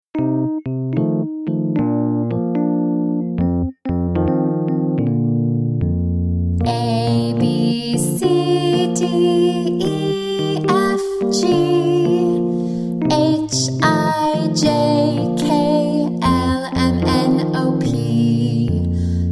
Demo MP3